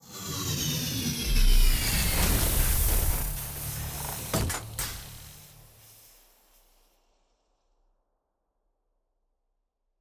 wind_down_exo.ogg